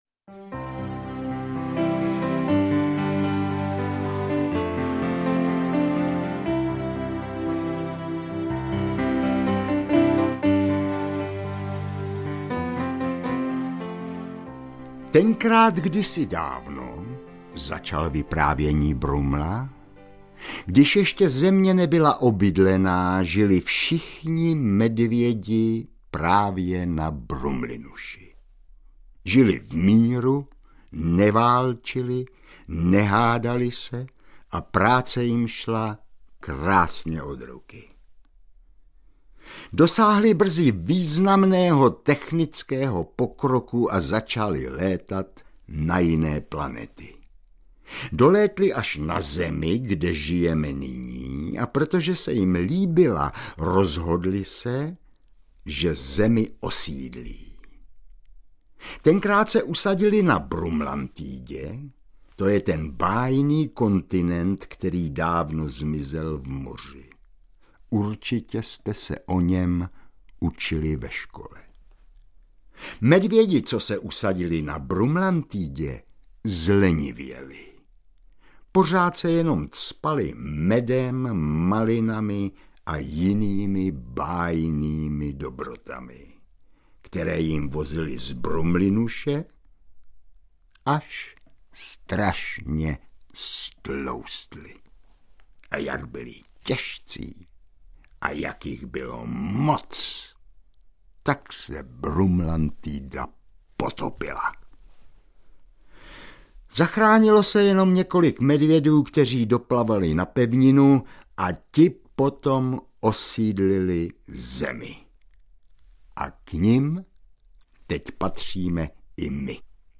Medvědí prázdniny audiokniha
Ukázka z knihy
• InterpretJan Tříska
medvedi-prazdniny-audiokniha